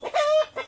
hen.ogg